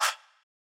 Chant - Regular Murda Chant.wav